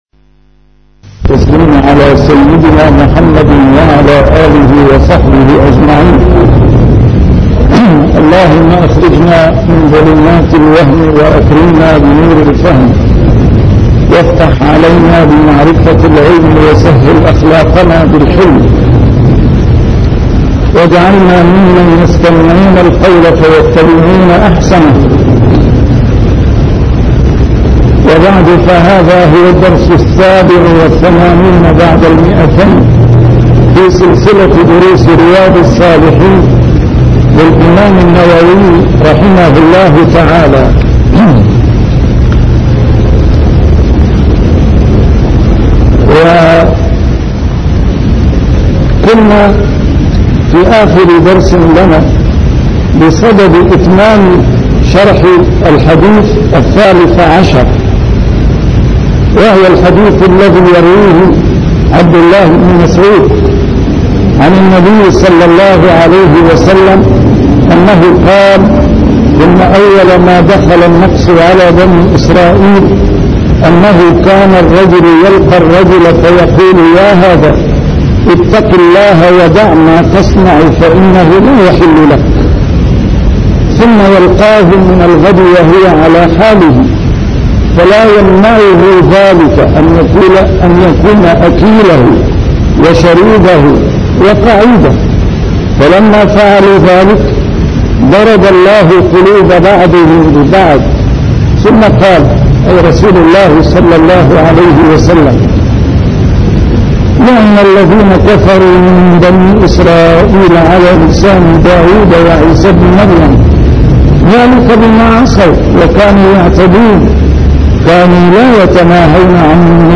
A MARTYR SCHOLAR: IMAM MUHAMMAD SAEED RAMADAN AL-BOUTI - الدروس العلمية - شرح كتاب رياض الصالحين - 287- شرح رياض الصالحين:الأمر بالمعروف تغليظ عقوبة من خالف